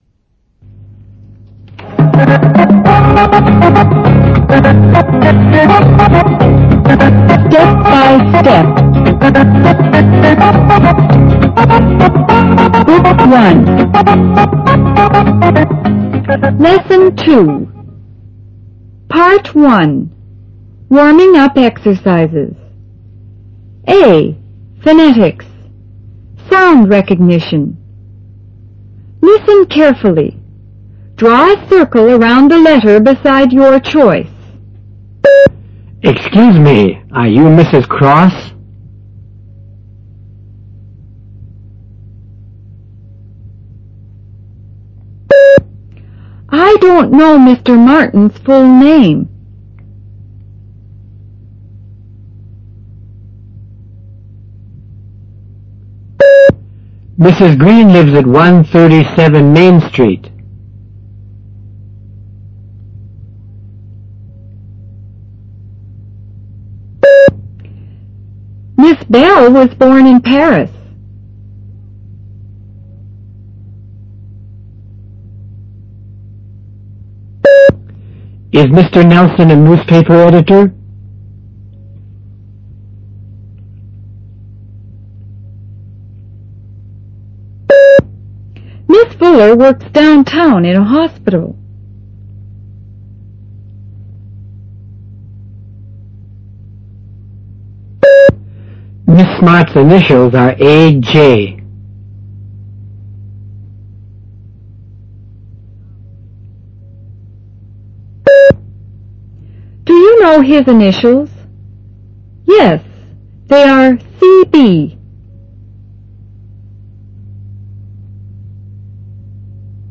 A. Phonetics:Sound Recognization
B. Sentences for Oral Repetition